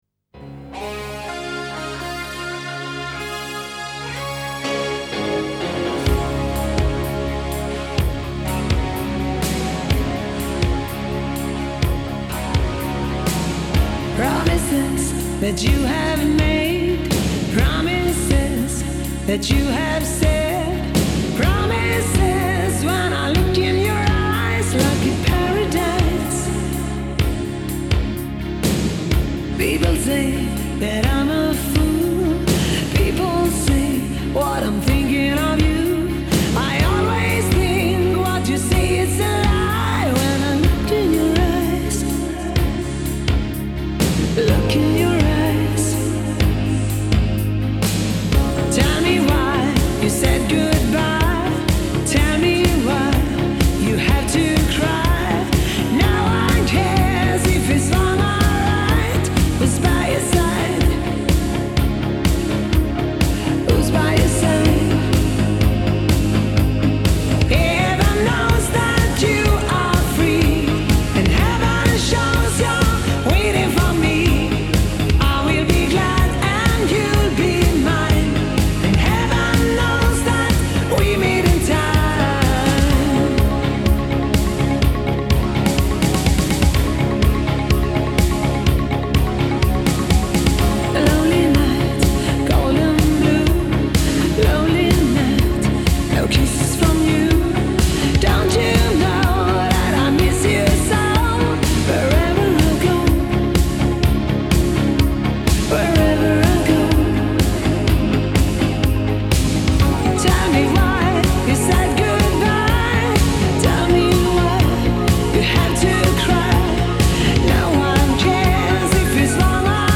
in einem Studio in Deutschland aufgenommen